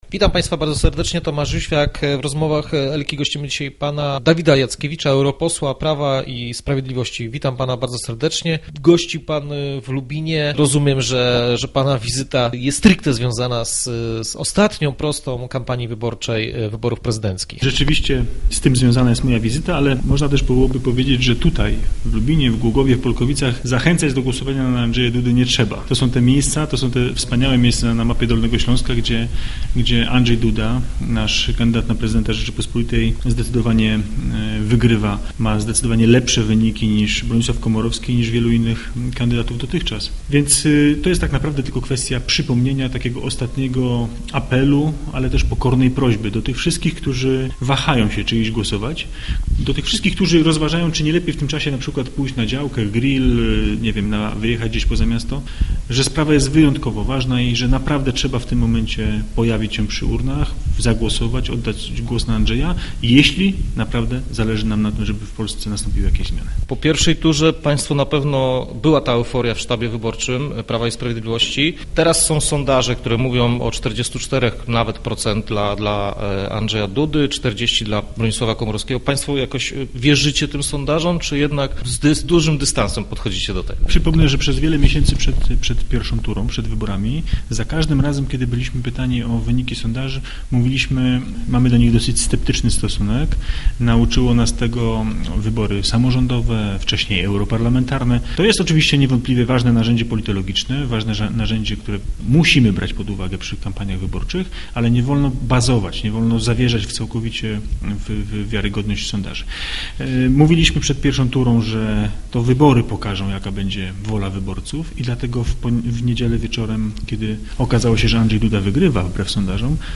Kampania przed drugą turą wyborów prezydenckich wchodzi w decydującą fazę. Naszym gościem był europoseł Prawa i Sprawiedliwości, Dawid Jackiewicz, który przebywał niedawno w Lubinie.